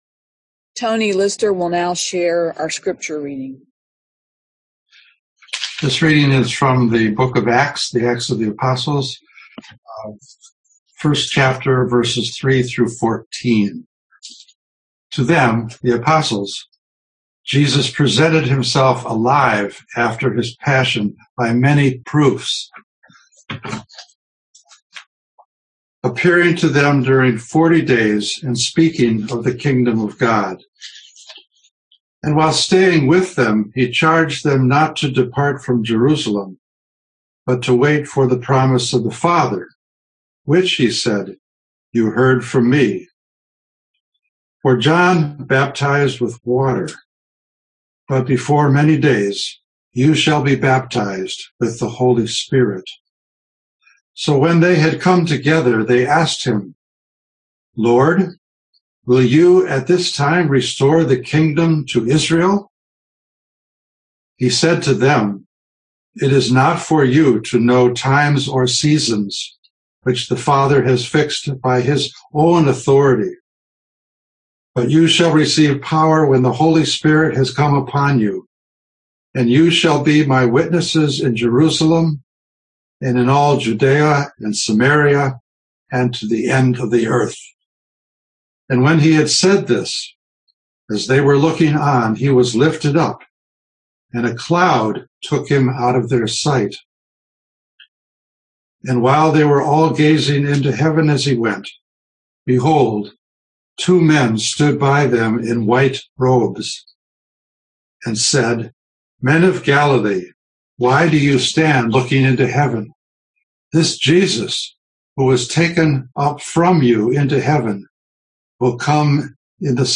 Recorded Sermons - The First Baptist Church In Ithaca